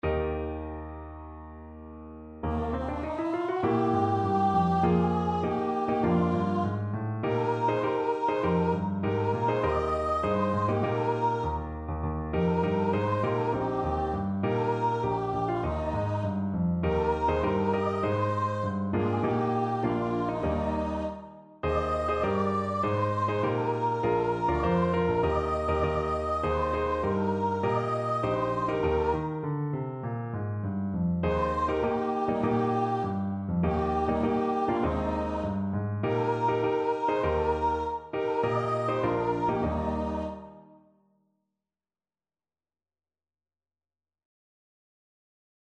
Voice
Eb major (Sounding Pitch) (View more Eb major Music for Voice )
4/4 (View more 4/4 Music)
Classical (View more Classical Voice Music)